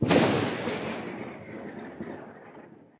sounds_thunder_02.ogg